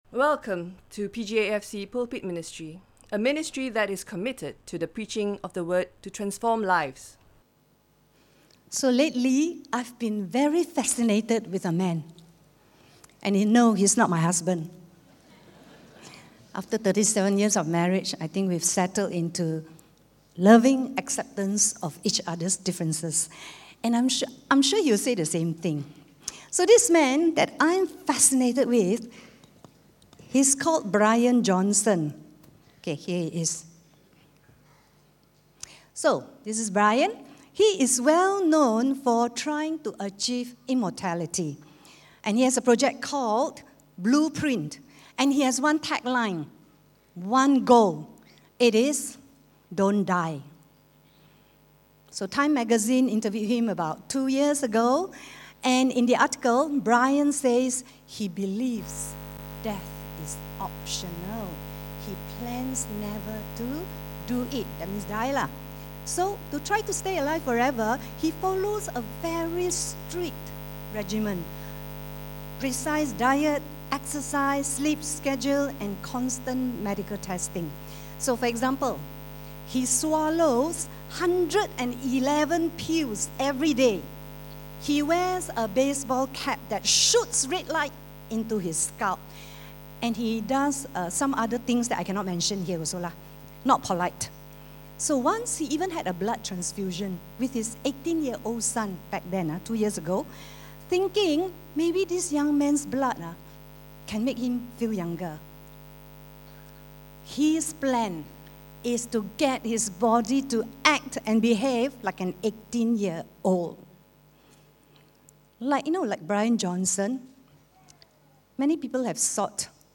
PJEFC's Christmas Service 2025
Listen to Sermon Only